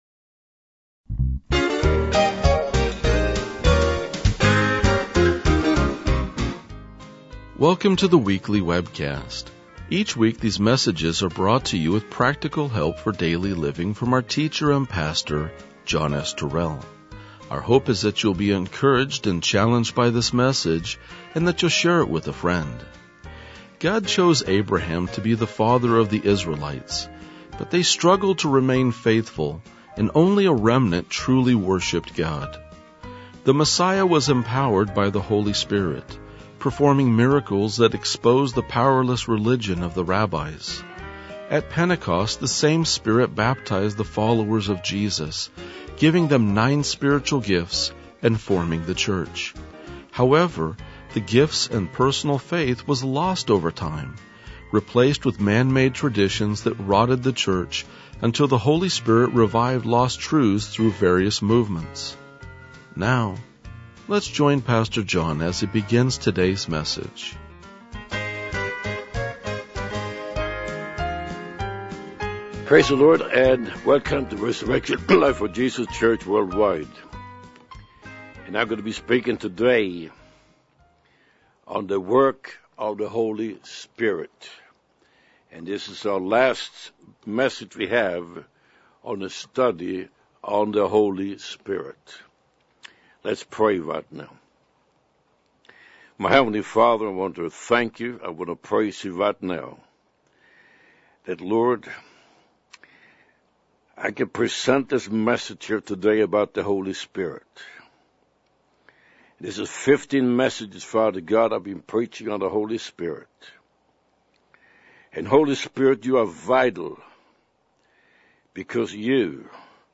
RLJ-2031-Sermon.mp3